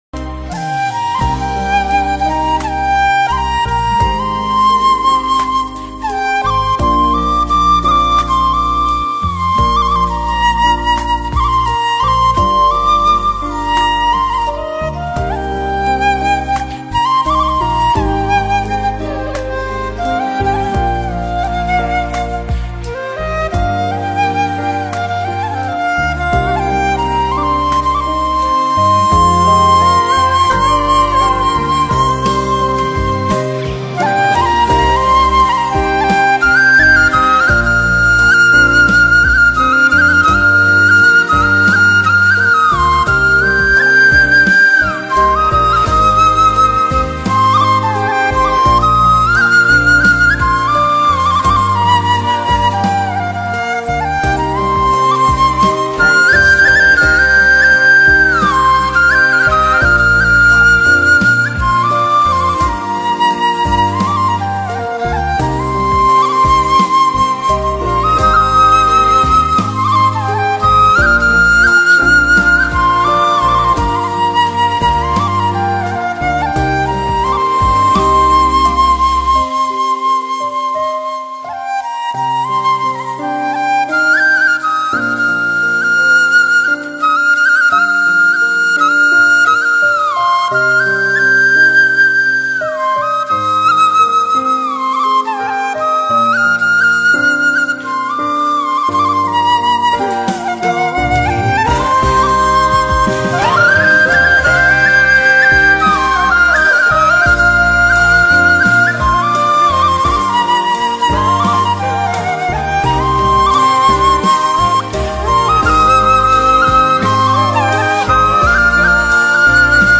giai điệu buồn da diết và sâu lắng.
bản nhạc không lời